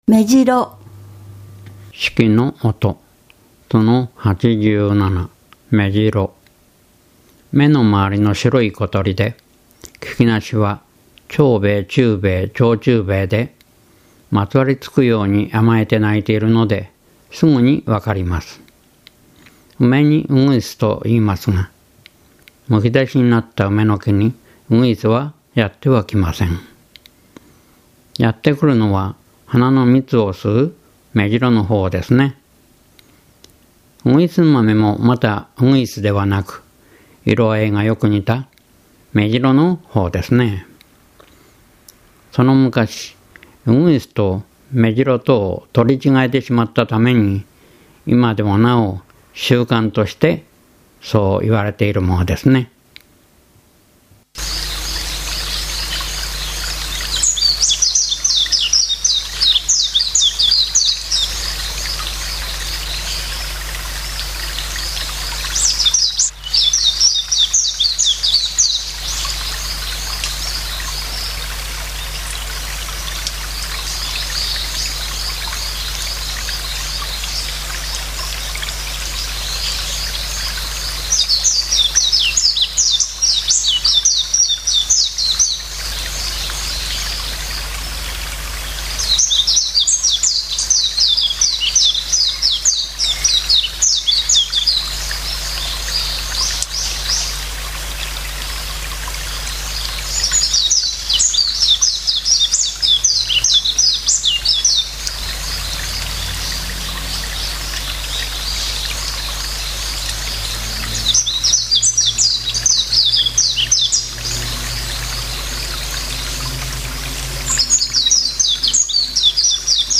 鳥の声
めじろ（目白）